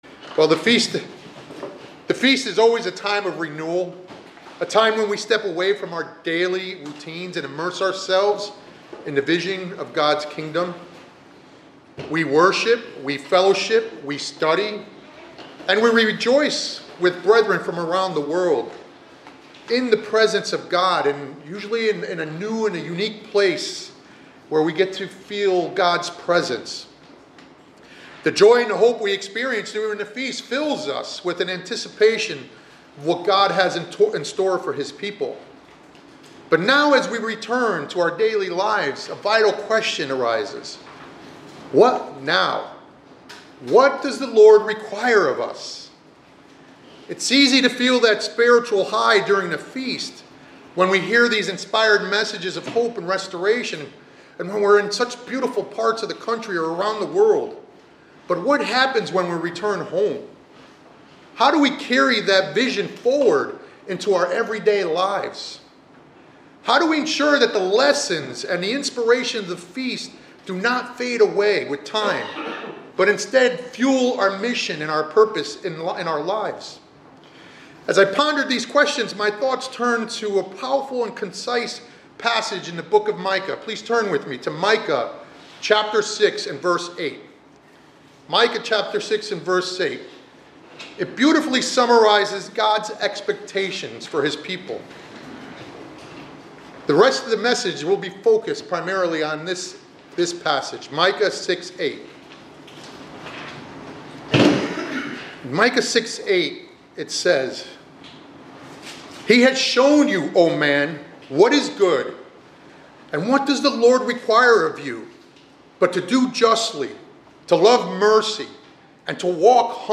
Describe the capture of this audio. This sermonette delivered after the Feast of Tabernacles reflects on how the spiritual renewal and inspiration experienced during the Feast should be carried into daily life.